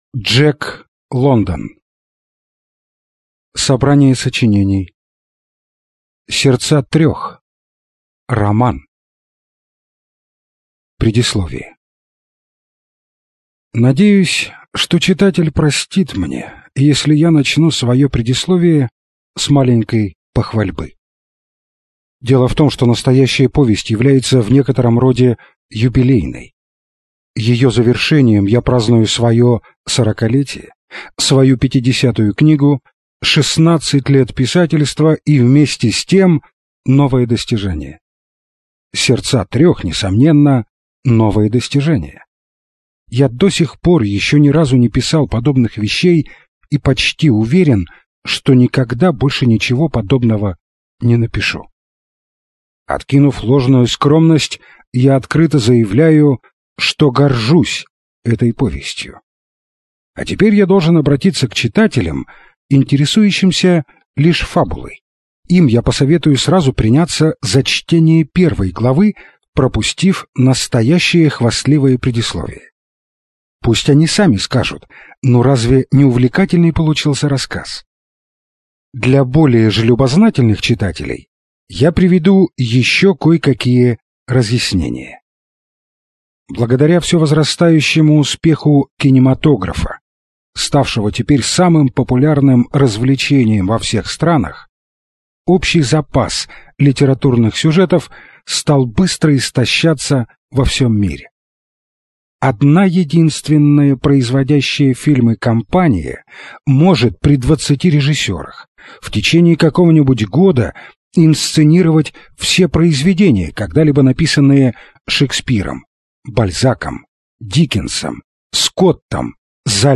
Аудиокнига Сердца трех - купить, скачать и слушать онлайн | КнигоПоиск